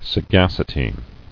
[sa·gac·i·ty]